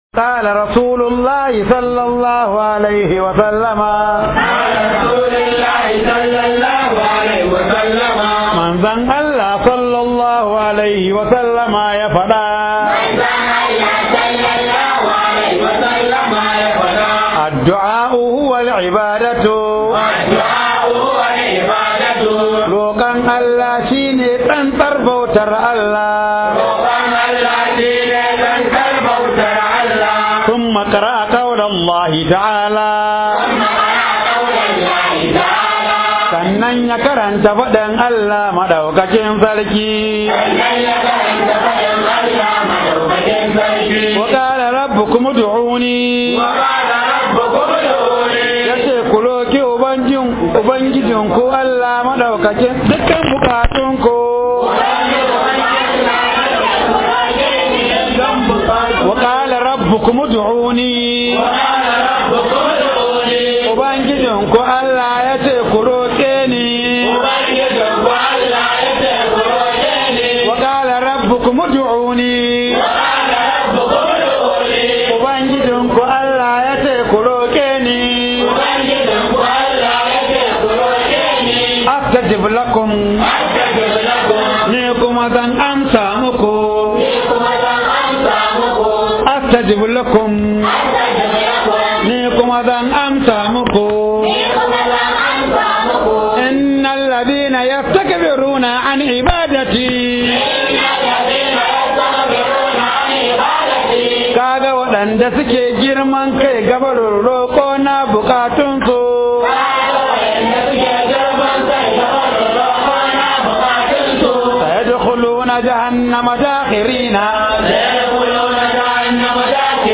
008 Karatun Hadisi Da_Yara.mp3